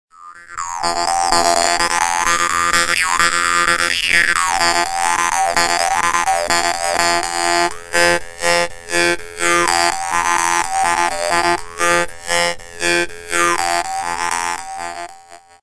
Die vietnamesischen Maultrommeln zeichnen sich durch ihre einfache Spielbarkeit, ihren schönen, obertonreichen Klang und den günstigen Preis aus.
Dabei erinnert ihr Sound an elektronische Klänge analoger Synthesizer – ganz ohne Technik.
Hörprobe Dan Moi Standard 2: